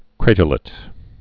(krātər-lĭt)